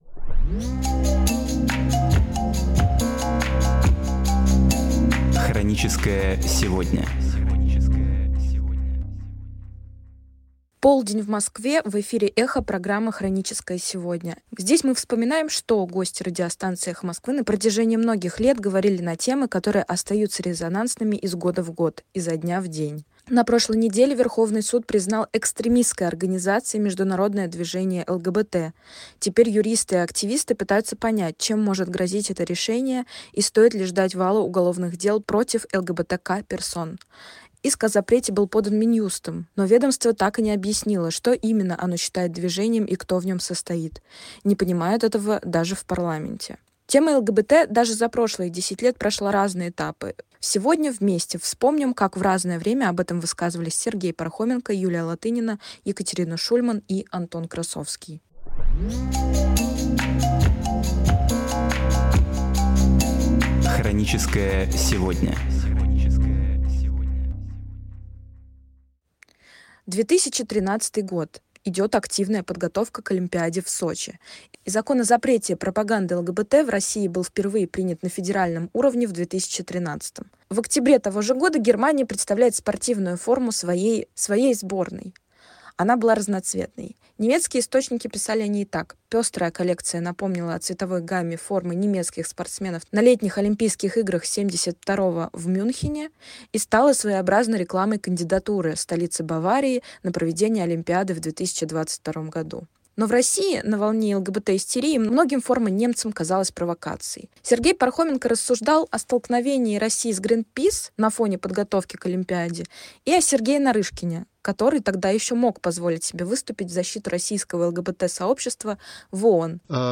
Архивные передачи «Эха Москвы» на самые важные темы дня сегодняшнего
Гости: Сергей Пархоменко, Юлия Латынина, Антон Красовский, Екатерина Шульман Отрывки из программ: «Суть событий», «Код доступа», «Особое мнение», «Статус»